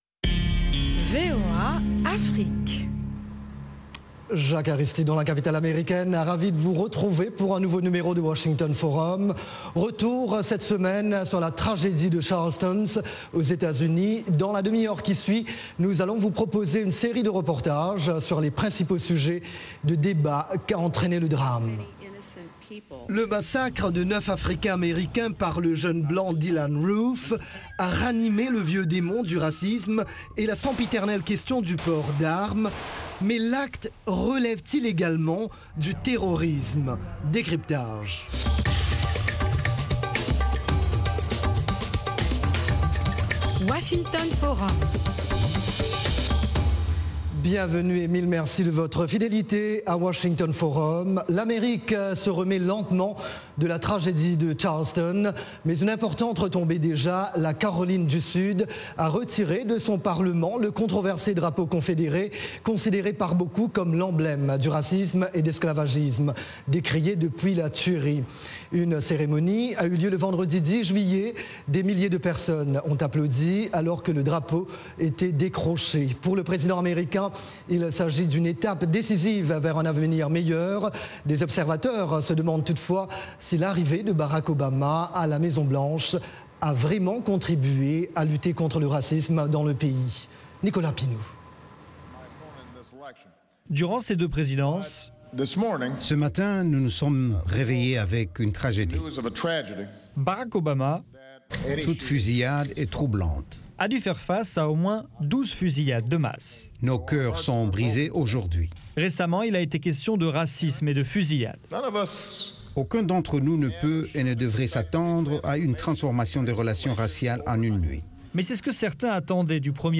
Economie, politique, santé, religion, sports, science, multimédias: nos experts répondent à vos questions en direct, via des Live Remote, Skype, et par téléphone de Dakar à Johannesburg, en passant par le Caire, New York, Paris et Londres. Cette émission est diffusée en direct par satellite à l’intention des stations de télévision et radio partenaires de la VOA en Afrique francophone.